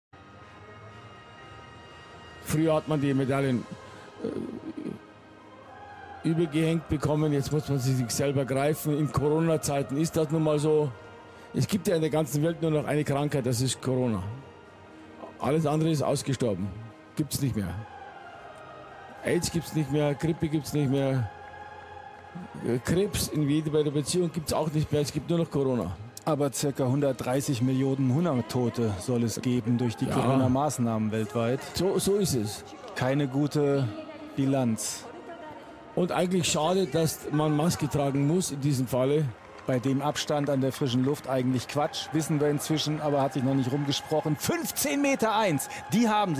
Wer vor einigen Tagen bei der Olympia-Übertragung die Live-Berichterstattung zur Verleihung der Medaillen nach dem Dreisprung-Finale auf Eurosport sah, wird sich an das berühmtes Märchen von Andersen "Der Kaiser ist nackt, jeder weiß es – doch wehe einer sagt es" erinnert fühlen.
Angesichts dieser ritualisierten Pandemie-Neuerung brach es aus den beiden Kommentatoren heraus und beide machten ihrem Unmut über die wahnhafte Corona-Manie Luft.